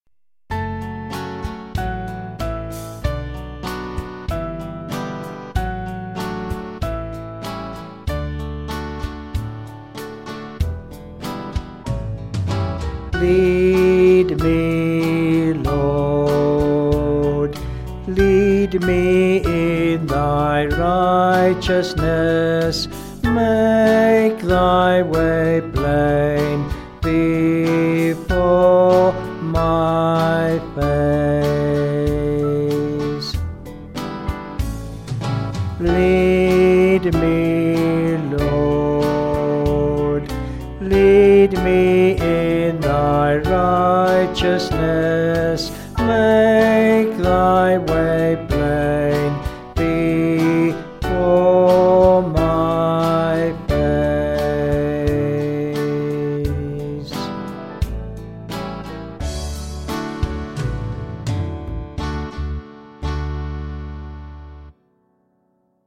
Vocals and Band   263.8kb Sung Lyrics